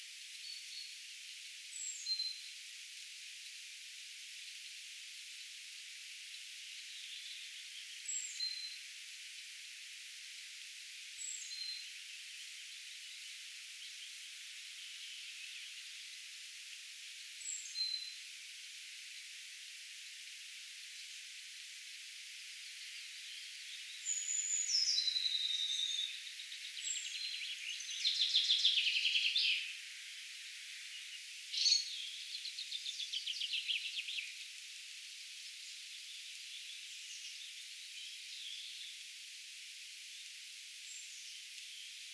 sinitiaisen laulussa kuin
pussitiaisen vihellyksiä?
Kiinnitin huomiota vain korkeisiin ääniin,
Korkeat äänet pelkästään muistuttavat
sinitiaisen_laulussa_ehkapa_hieman_pussitiaismaisia_korkeita_aania.mp3